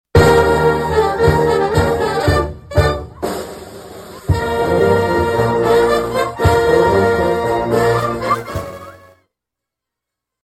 The rain held off, so the Emporia Municipal Band opened up its regular season at Fremont Park instead of Albert Taylor Hall on Thursday night.